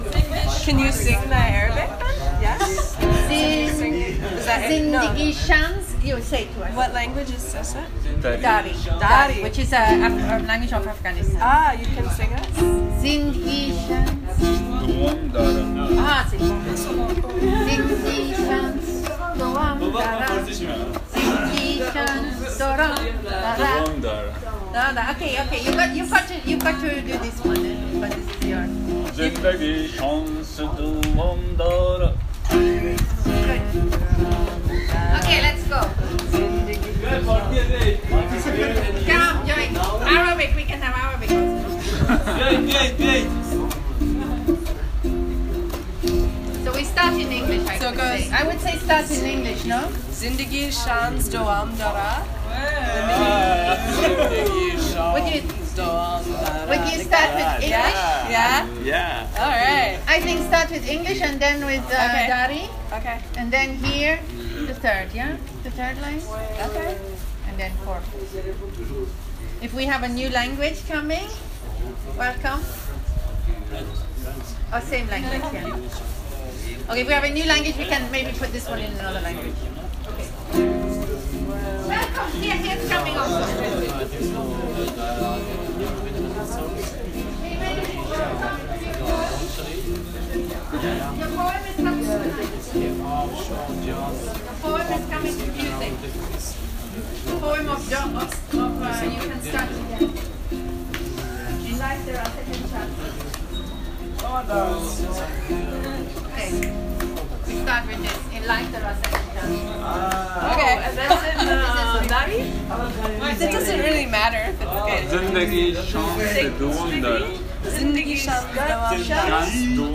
These are recordings from the center that show the process of writing the song:
life-is-here-and-now-writing-the-song-at-the-center-01.m4a